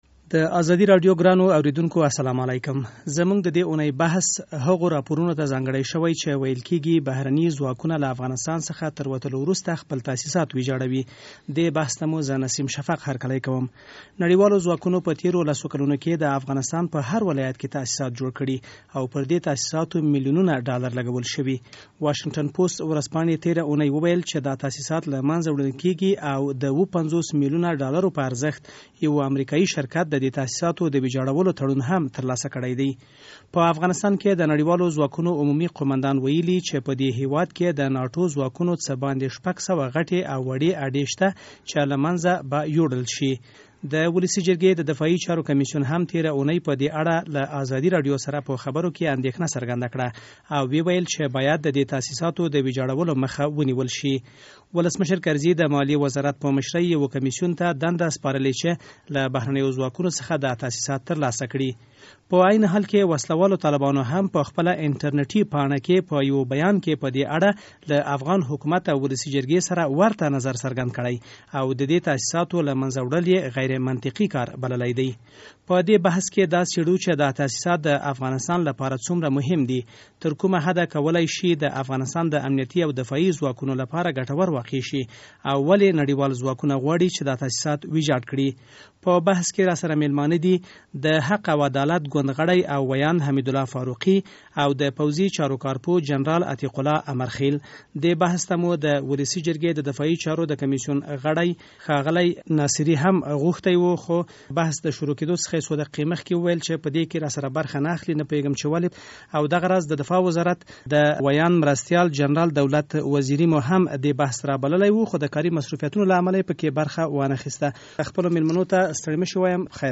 زموږ د دې اونۍ بحث هغو راپورونو ته ځانګړی شوی چې ویل کیږي، بهرني ځواکونه له افغانستان څخه تر وتلو وروسته خپل تاسیسات ویجاړوي.